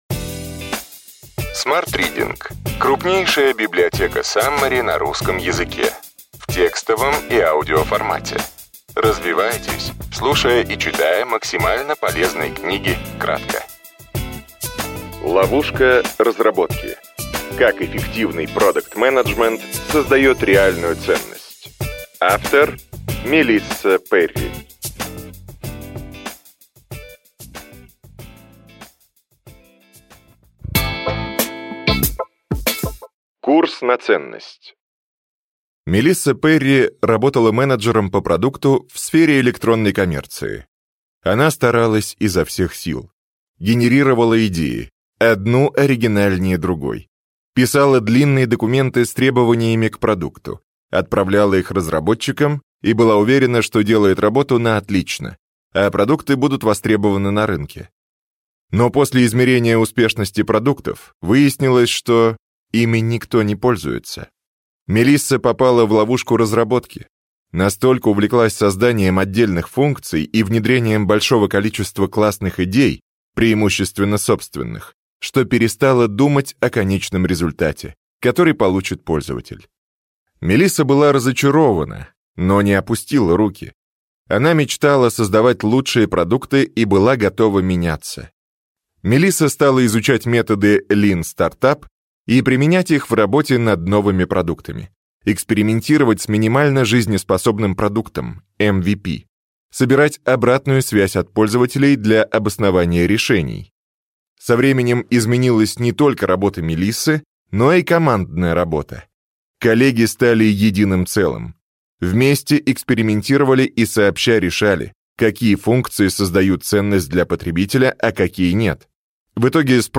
Аудиокнига Ключевые идеи книги: Ловушка разработки. Как эффективный продакт-менеджмент создает реальную ценность.